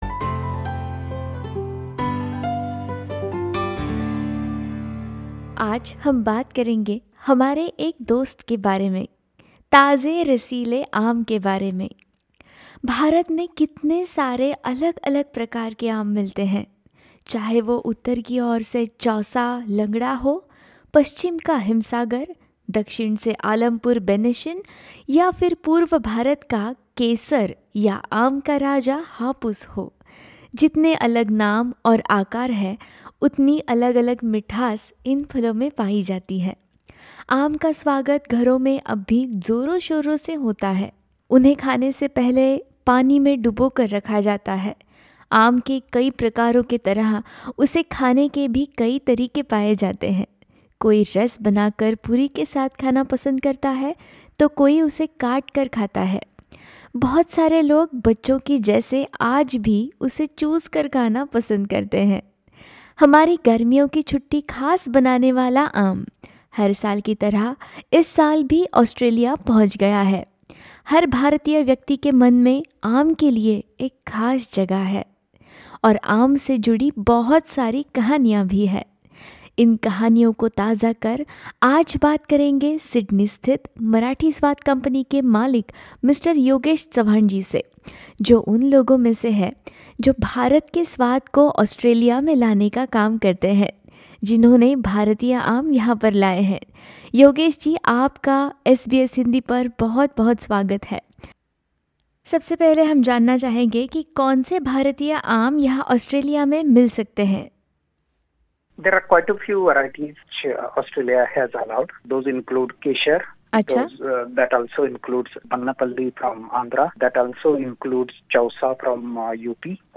यही मीठे भारतीय आम अब ऑस्ट्रेलिया में भी आ चुके है। सुनते है उससे जुडी यह खास रिपोर्ट।